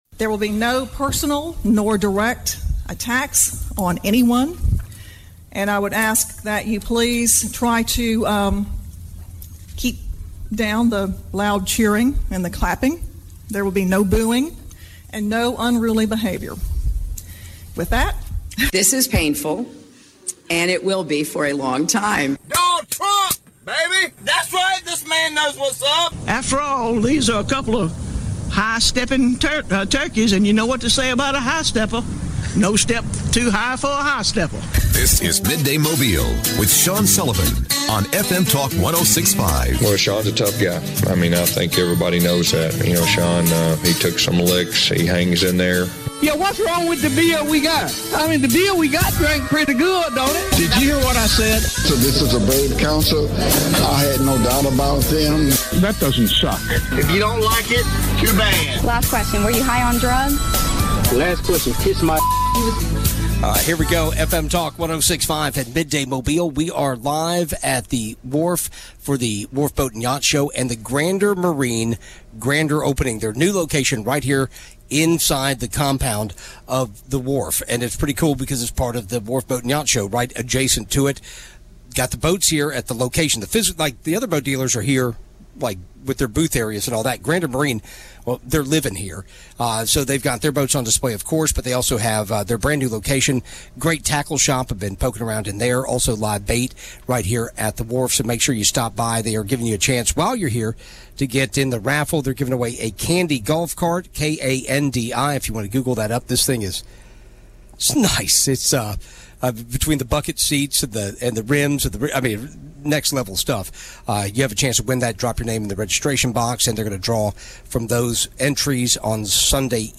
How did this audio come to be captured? Midday Mobile - Live from Grander Marine in Orange Beach